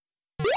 jump_ledge.wav